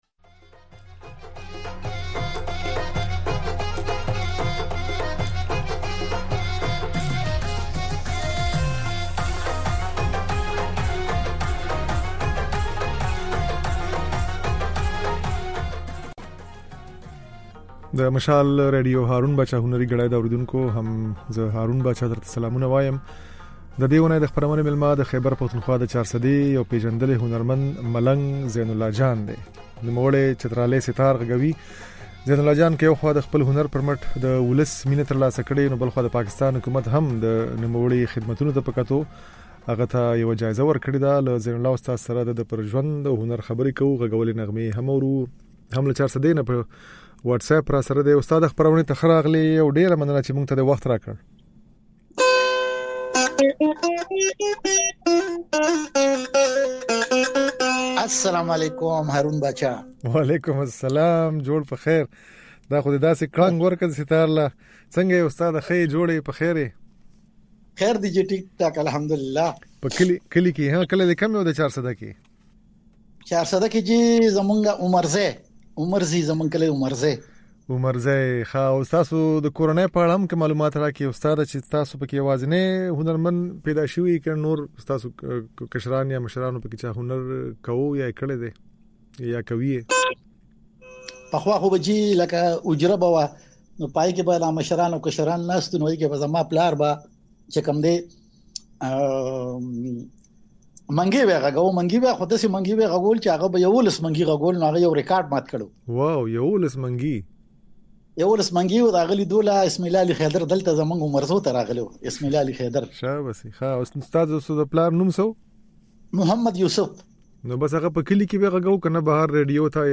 د چترالي سيتار ځينې نغمې يې په خپرونه کې اورېدای شئ.